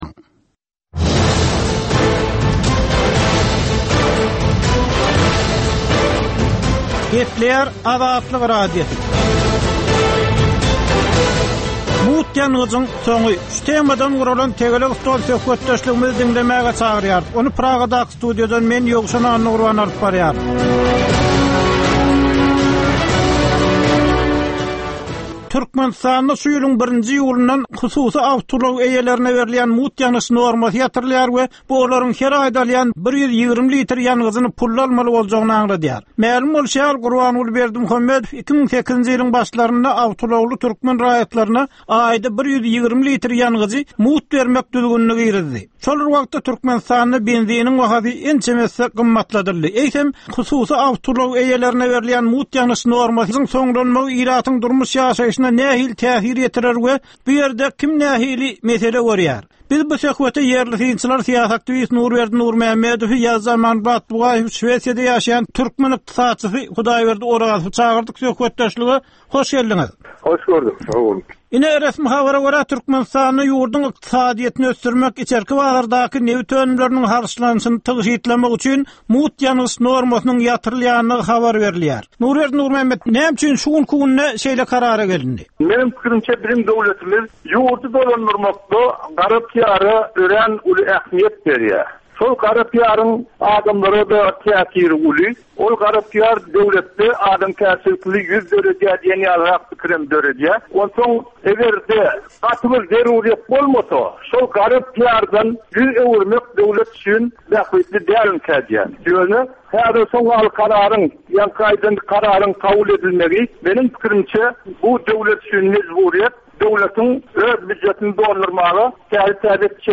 “Tegelek stol” söhbetdeşligi
Türkmenistanly synçylaryň gatnaşmagynda, ýurduň we halkyň durmuşyndaky iň möhüm meseleler barada töwerekleýin gürrüň edilýän programma. Bu programmada synçylar öz pikir-garaýyşlaryny aýdyp, jedelleşip bilýärler.